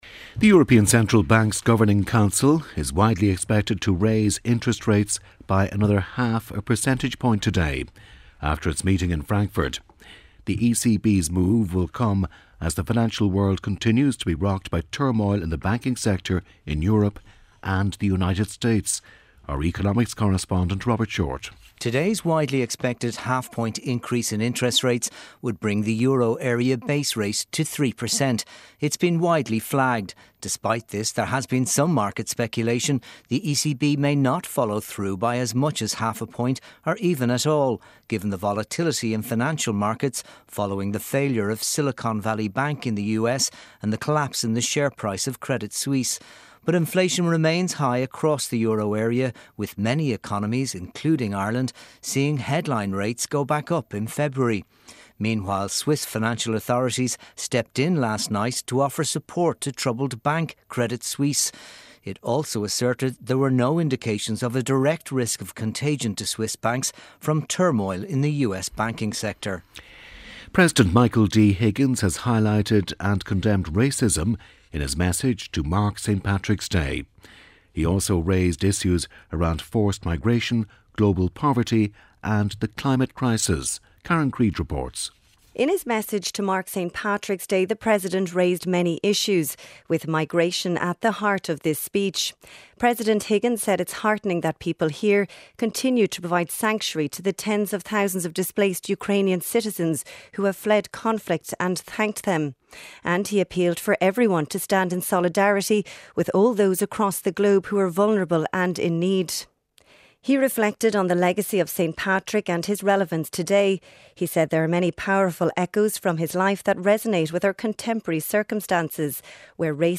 8am News Bulletin - 16.03.2023